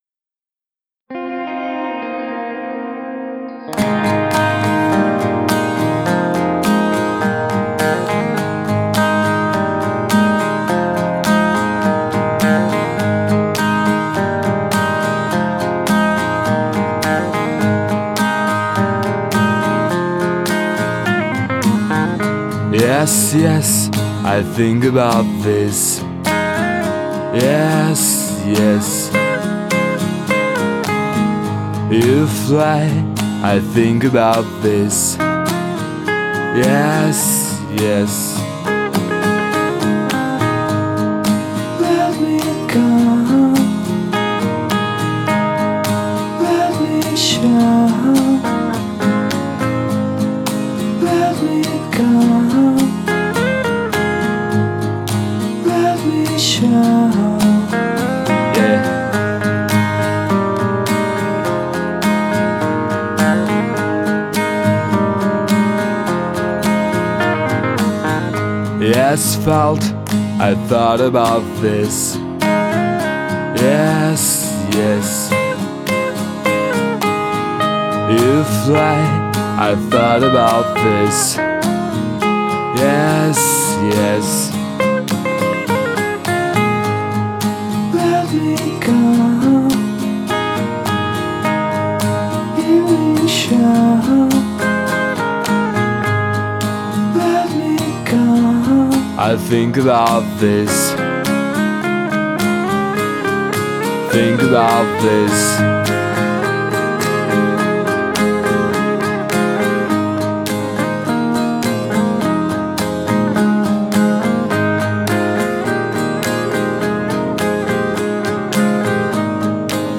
wohlklingender Akustik-Pop
Genre: Acoustic / Pop